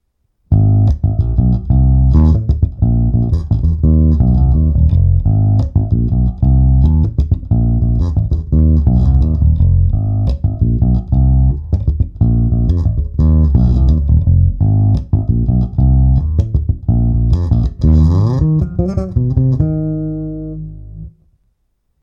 Aparát jsem snímal z přibližně půlmetrové vzdálenosti studiovým kondenzátorovým mikrofonem Joemeek JM 37.
Všechny nahrávky jsou bez dodatečných úprav. Neodstraňoval jsem šum, neupravoval ekvalizaci, jen jsem nahrávky znormalizoval.
Nejprve sem hodím dvě ukázky sejmuté mikrofonem: